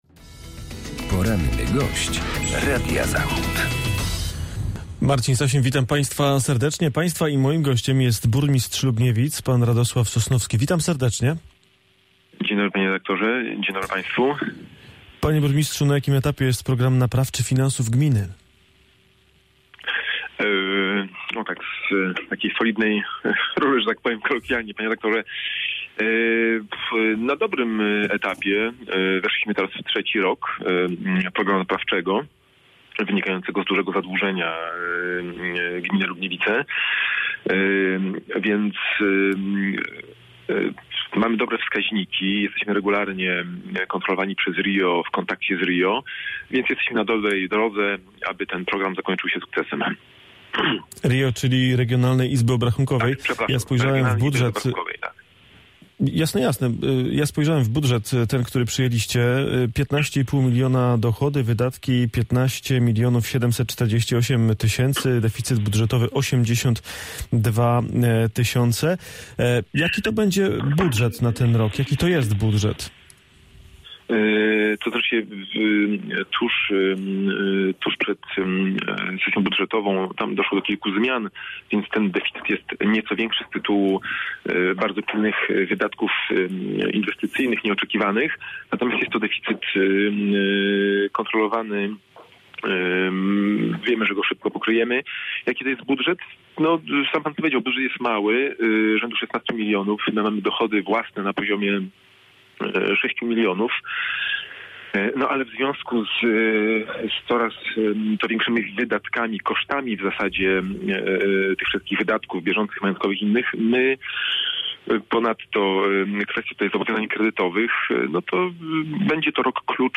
Radosław Sosnowski, burmistrz Lubniewic